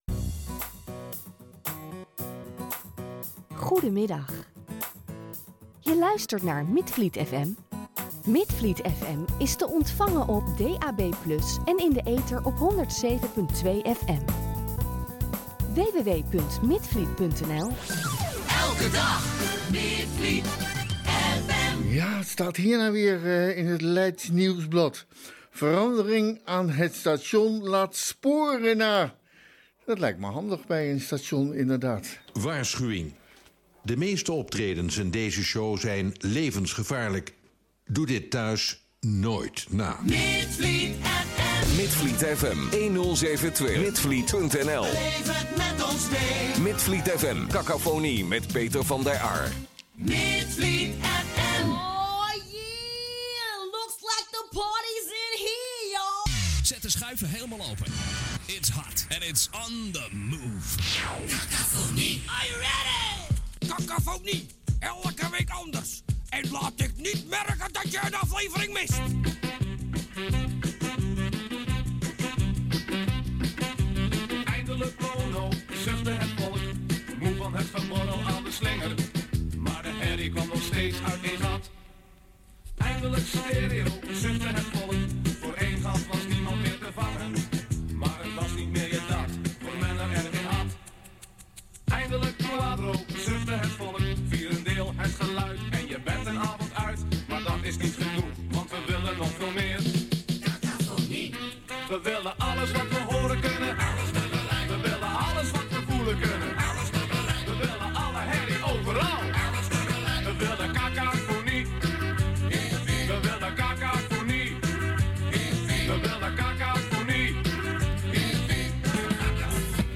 Via deze link kun je het interview en het nummer terugluisteren.